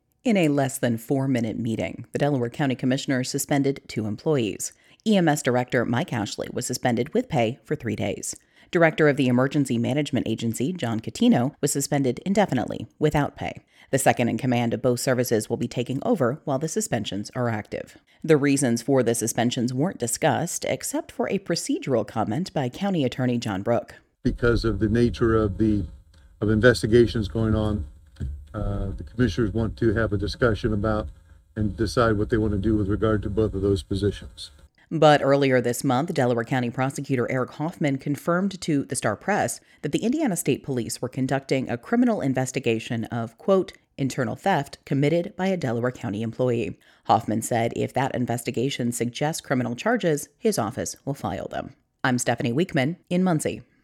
Indiana Public Radio